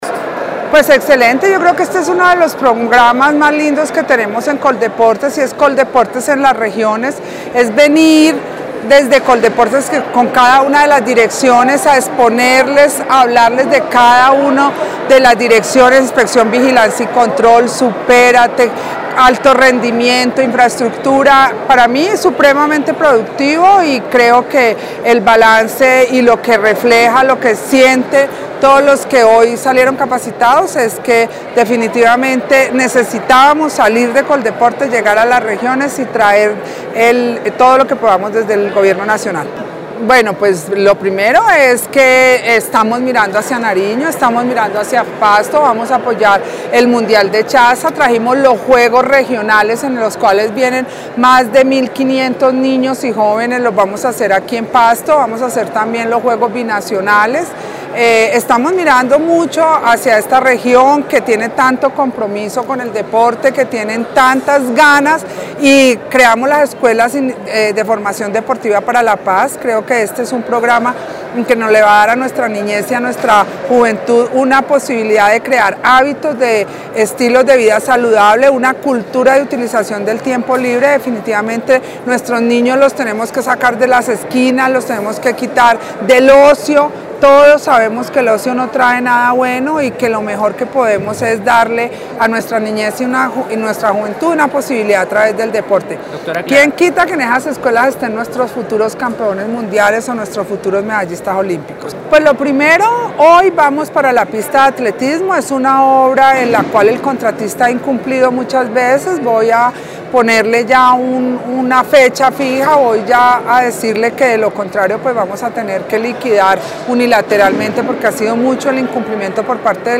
En el evento de clausura, hizo presencia la directora de Coldeportes, Clara Luz Roldán, quien le anunció a la comunidad, que Nariño se ha destacado por su trabajo juicioso y transparente en lo respecta al deporte, motivo por el cual aseveró que los entes deportivos municipales y la Secretaría de Recreación y Deportes de Nariño, asumirán la realización del campeonato Regional Pacífico de los Juegos Supérate Intercolegiados, también de los Juego de Mar y Playa, y de los Juegos Binacionales e Indígenas.
Clara Luz Roldán, Directora de Coldeportes.
CLARA_LUZ_ROLDN_-_DIRECTORA_DE_COLDEPORTES.mp3